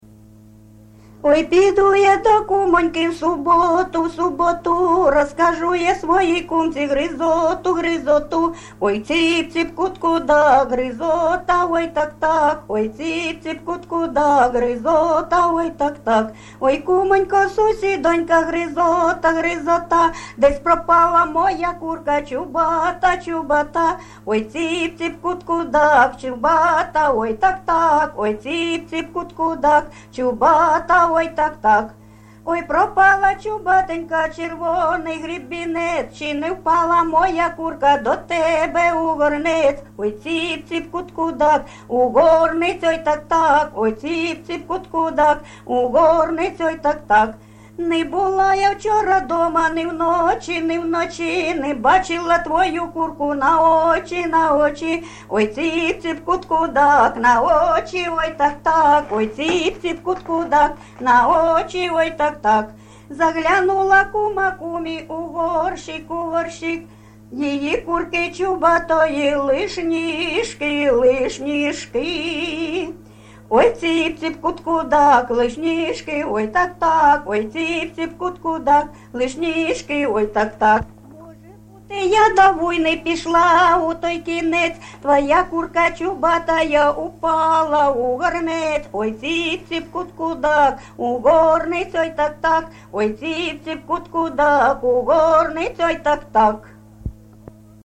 ЖанрПісні з особистого та родинного життя, Жартівливі
Місце записум. Часів Яр, Артемівський (Бахмутський) район, Донецька обл., Україна, Слобожанщина